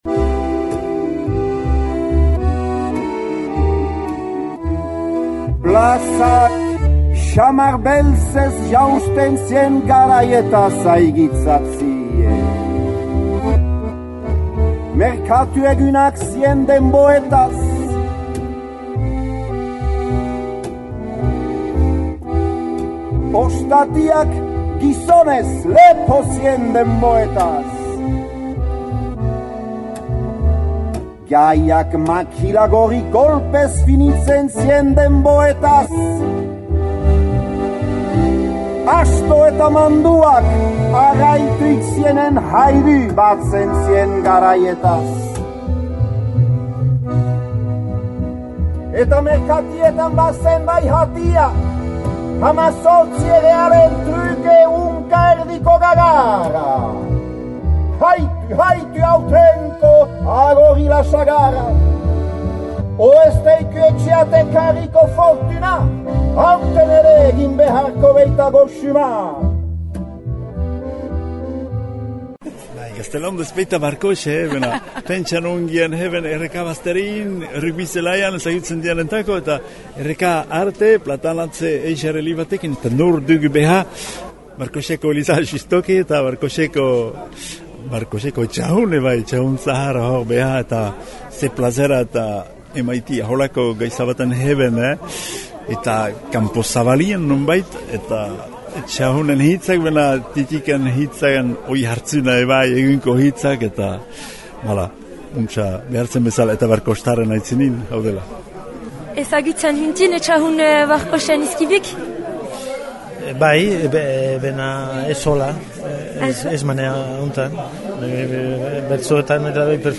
Müsika tresnetarik lagüntürik, soinü desbardin elibat ere gehitürik eta kantoreetarik partitzez beste testo elibat izkiribatürik plantatü düe ikusgarria.